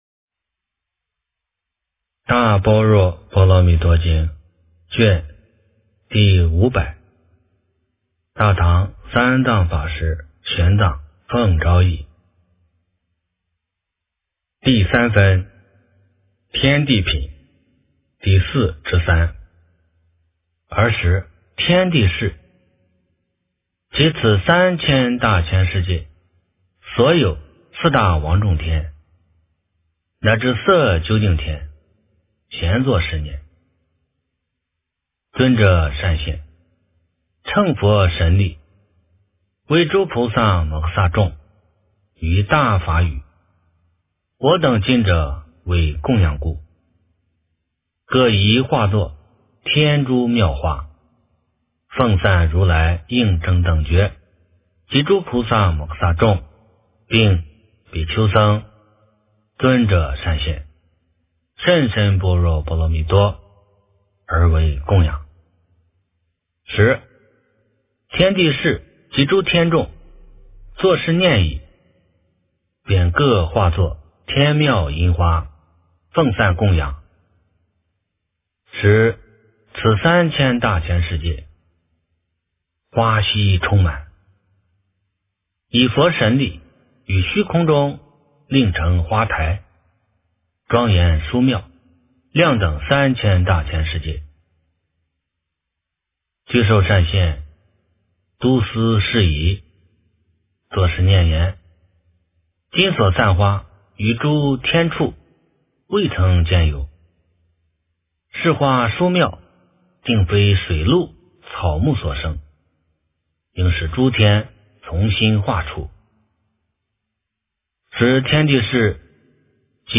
大般若波罗蜜多经第500卷 - 诵经 - 云佛论坛